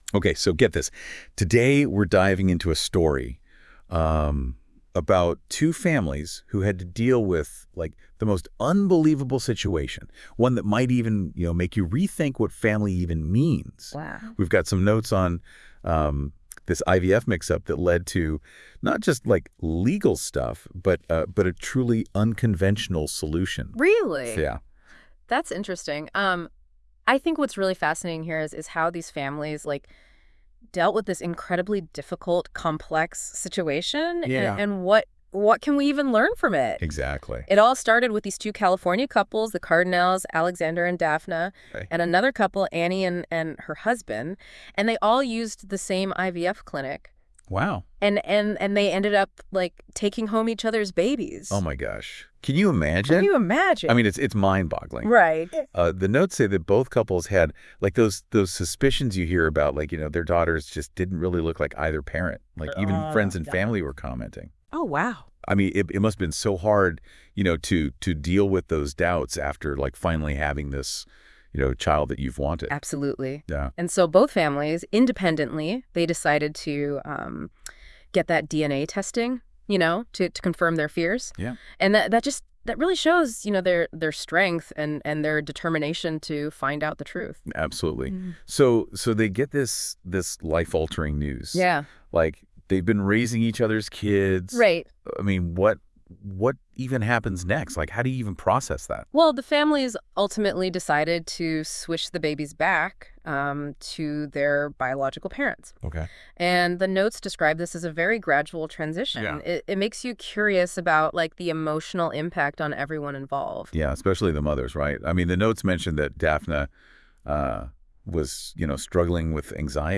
Listen to a convo on this situation.